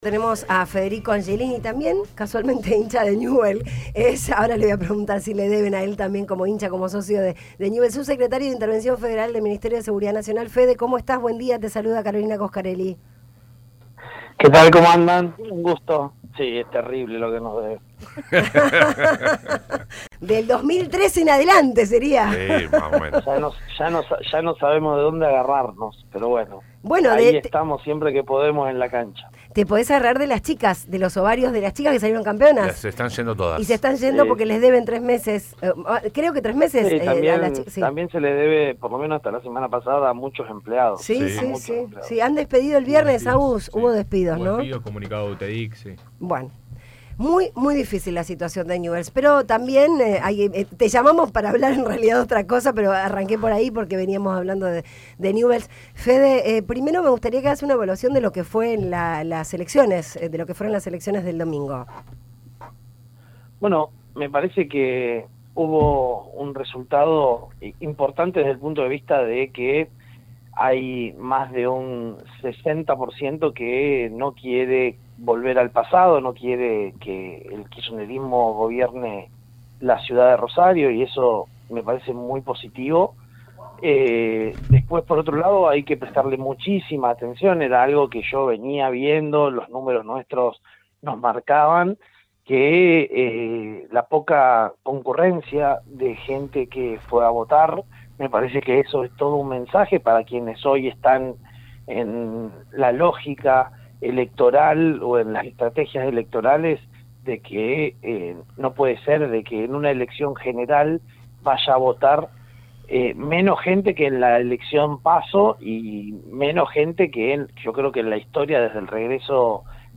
Federico Angelini, subsecretario de Intervención Federal del Ministerio de Seguridad Nacional habló en el programa Digamos Todo sobre las elecciones del pasado domingo en la provincia y la proyección política del periodista Juan Pedro Aleart, en cuyo armado de campaña estuvo como participante activo.